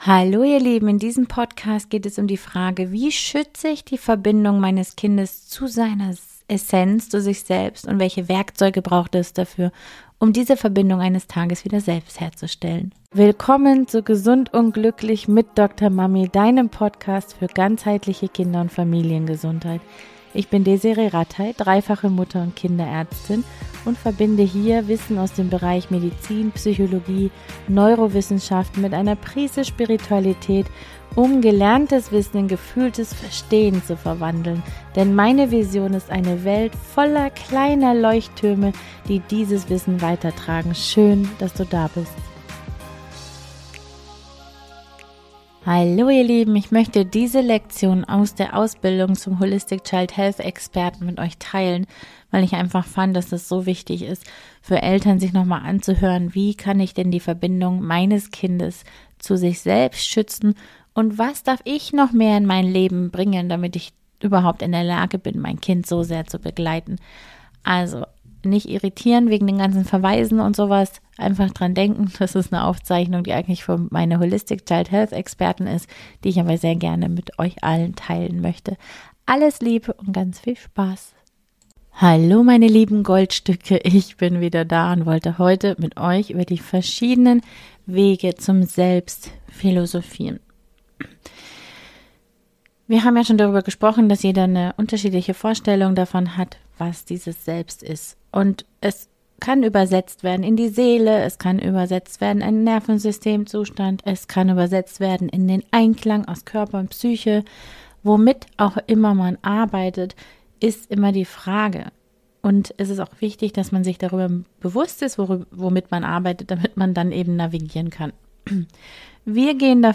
Diese Episode ist eigentlich eine Lektion aus meiner Ausbildung zum Holistic Child Health Expert aber ich wollte sie gerne mit euch allen teilen!